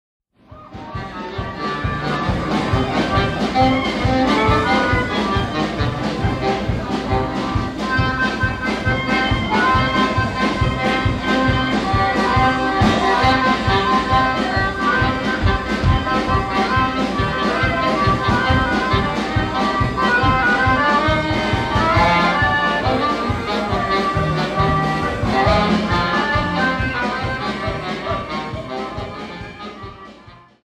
Hungarian Folk Music in the United States
This live recording was made at a Hungarian Club in Lake Ronkonkoma, New York on October 2, 1982. The occasion was the popular Harvest Dance, also referred to as the Grape Festival. The small club was packed.
The song cycle (beginning heard here) is a fragment of an eight minute long dance set known as “the gypsy czardas” or “the gypsy dance set”.